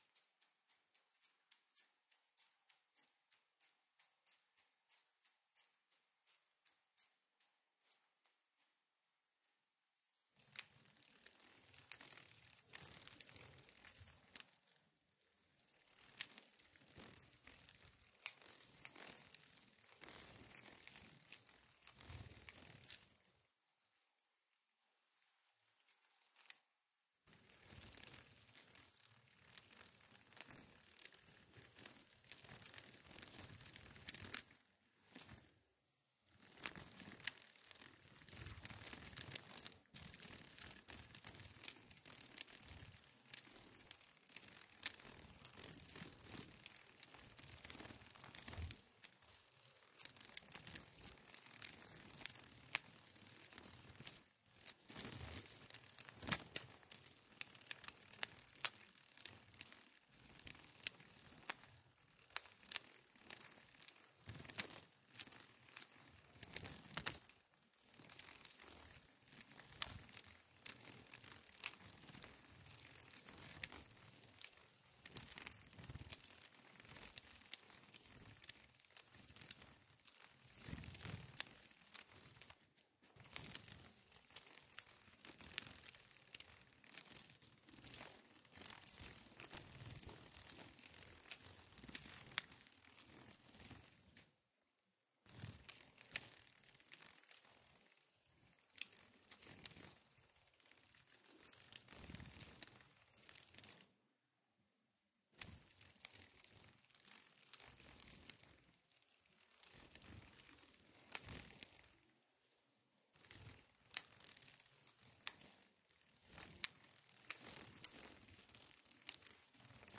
На этой странице собраны звуки бабочек и мотыльков – нежные шелесты крыльев, создающие атмосферу летнего сада или лесной поляны.